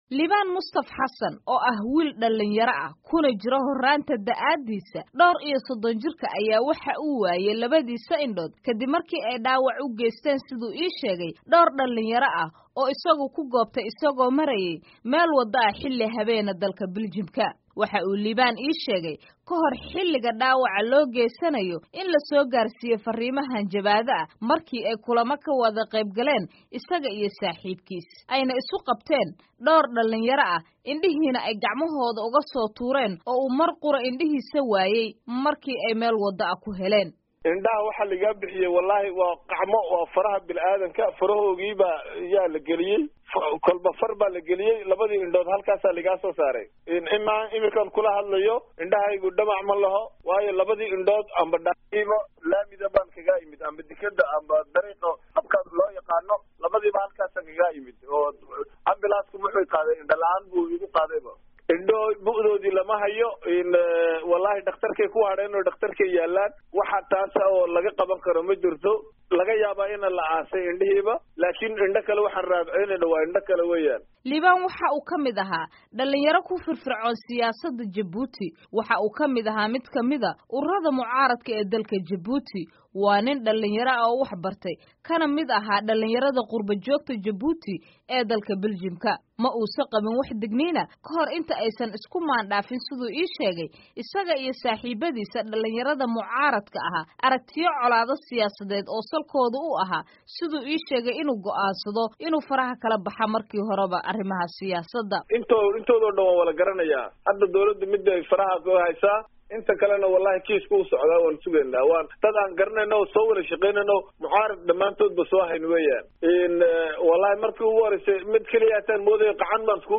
Warbixin: Xaaladda Wiil Indhaha Looga Riday Belgium